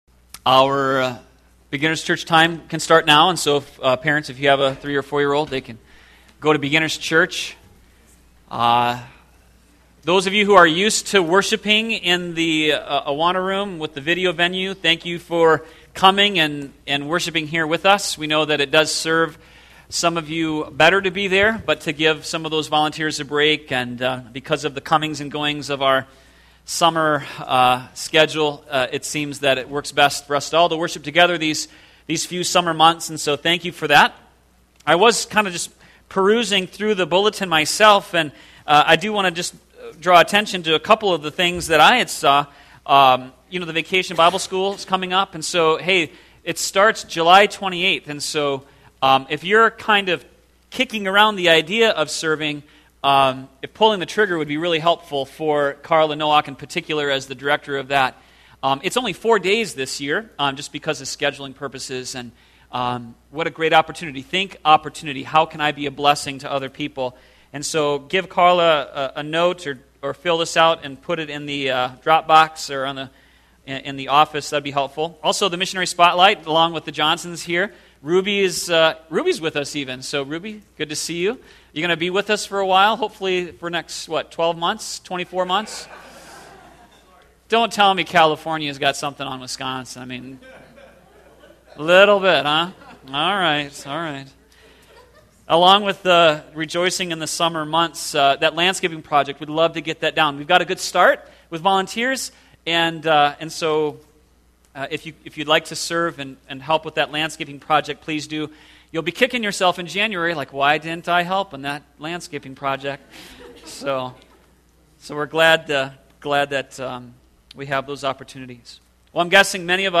sermon7614.mp3